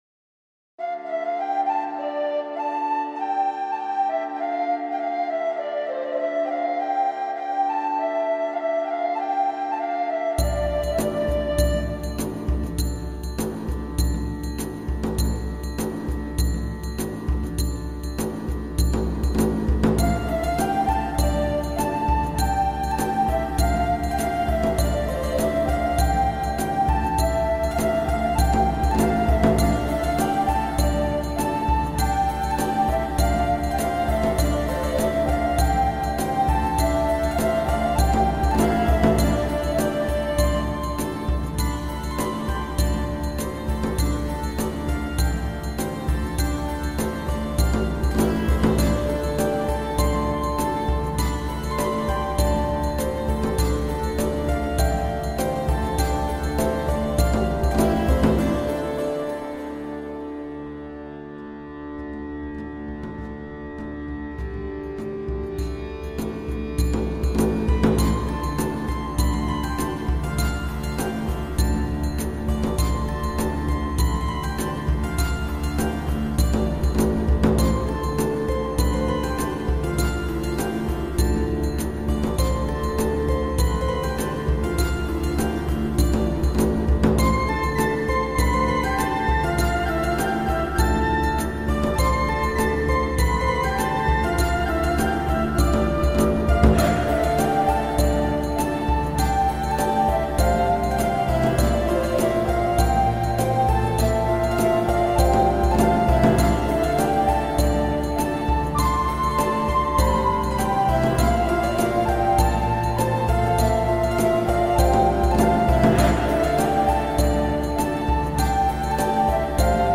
KGmxcMgTjGR_Música-Celta-Música-tradicional-CeltaCeltic-Chill-Out.mp3